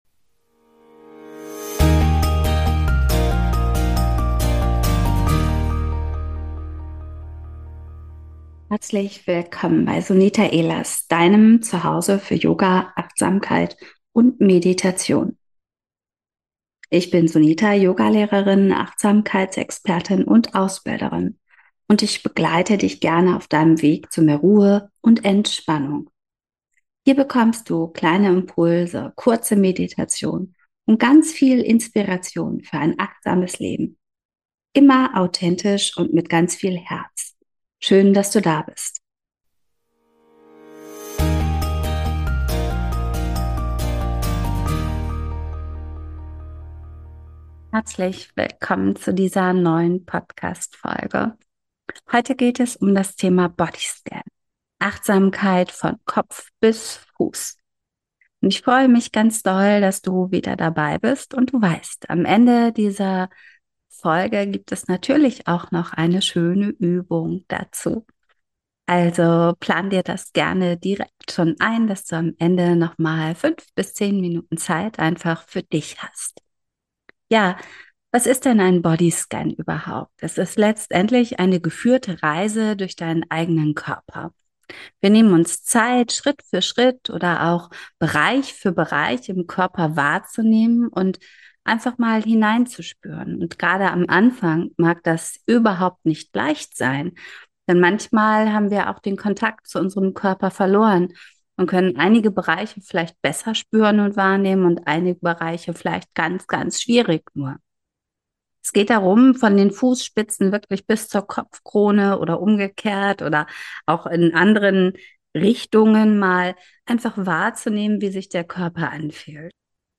Ein Body Scan ist im Grunde eine geführte Reise durch den eigenen Körper. Wir nehmen uns Zeit, Schritt für Schritt unseren Körper bewusst wahrzunehmen. Dabei geht es nicht darum, etwas zu verändern, zu analysieren oder zu bewerten.